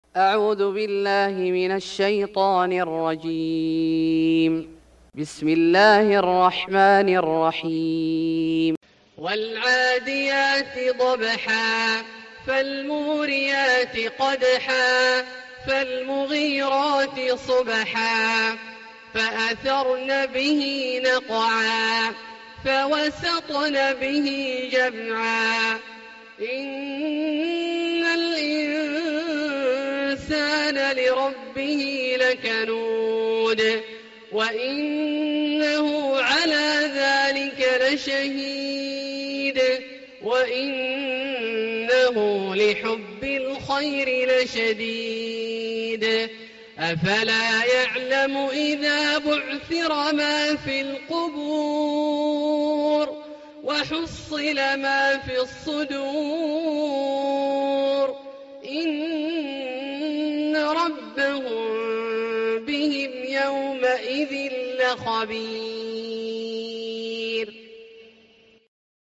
سورة العاديات Surat Al-Adiyat > مصحف الشيخ عبدالله الجهني من الحرم المكي > المصحف - تلاوات الحرمين